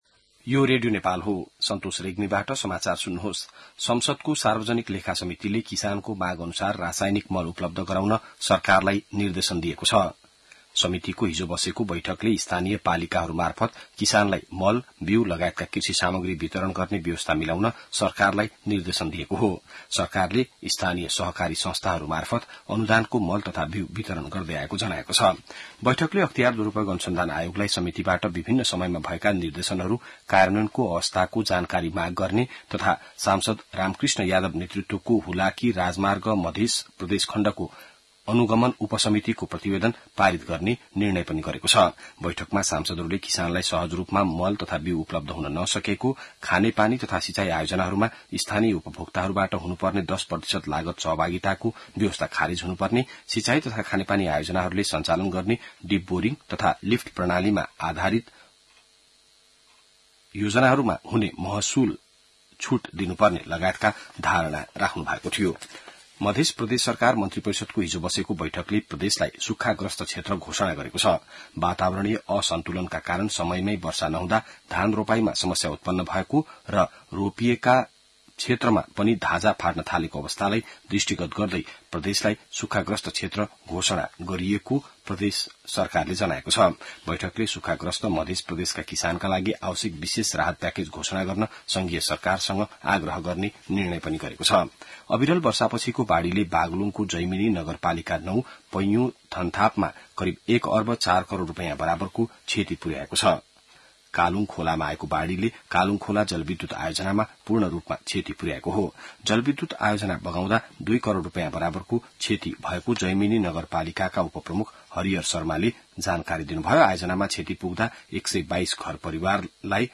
An online outlet of Nepal's national radio broadcaster
बिहान ६ बजेको नेपाली समाचार : २७ असार , २०८२